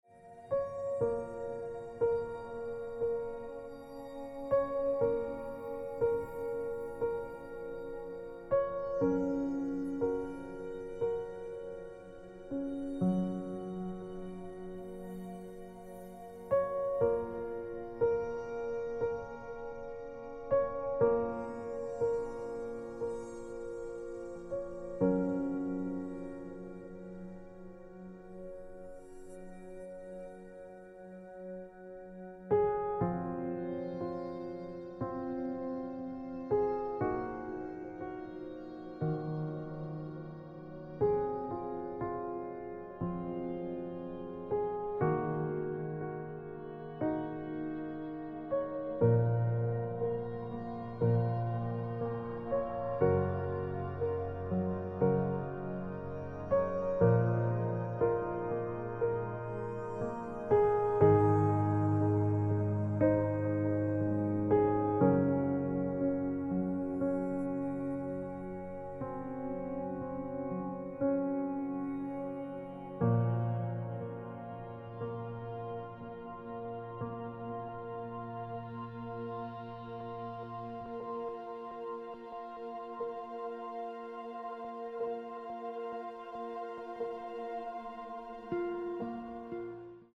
Dramatic, heartfelt and adventurous